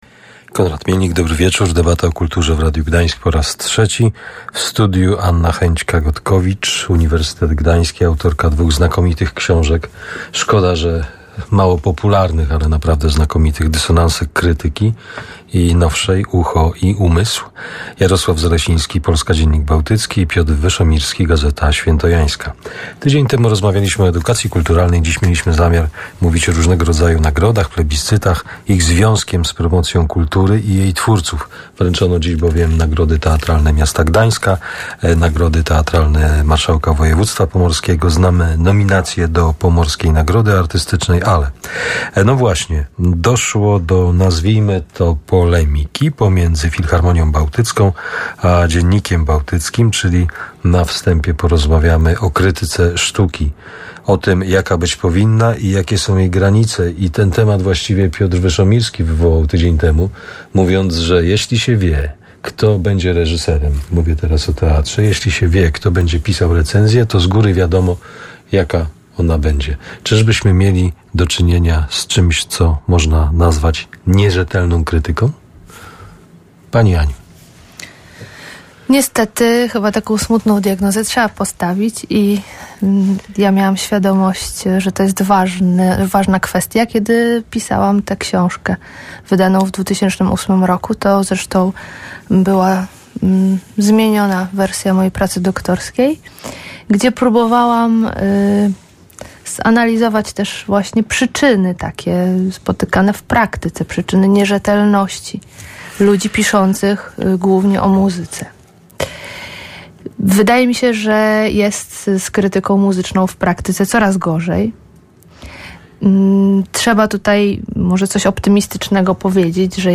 debata2503.mp3